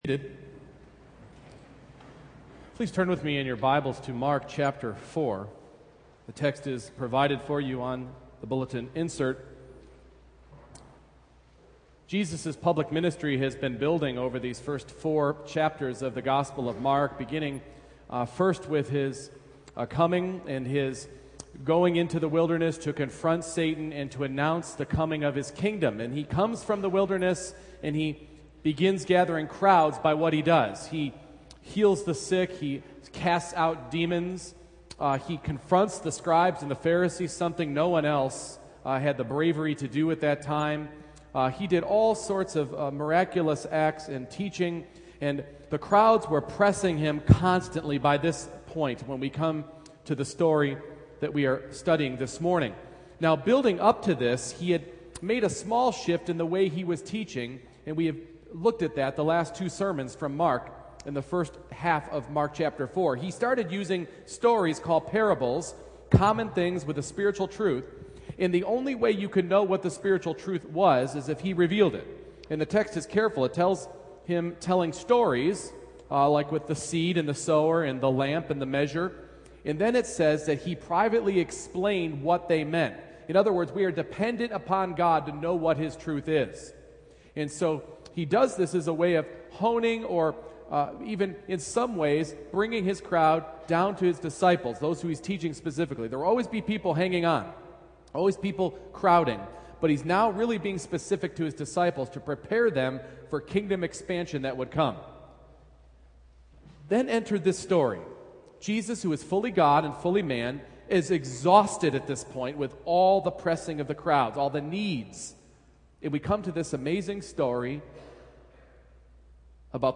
Mark 4:35-41 Service Type: Morning Worship No matter what storm you are going through